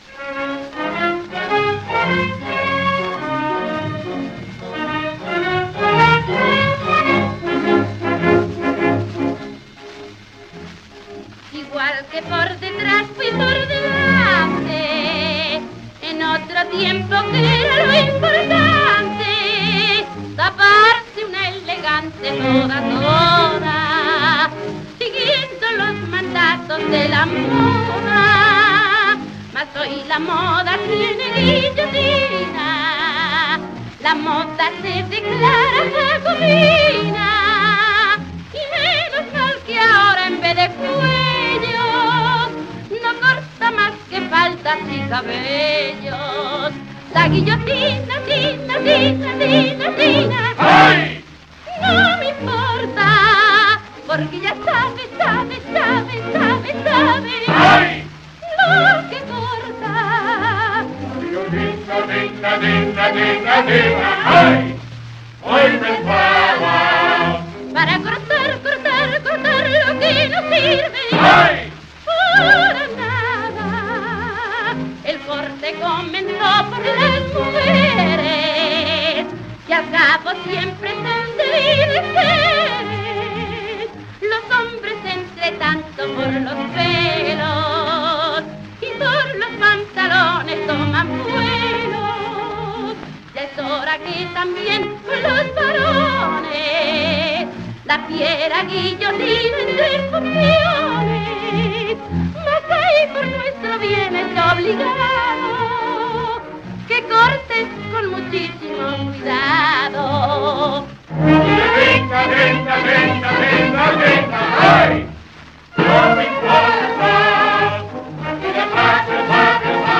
coro [78 rpm]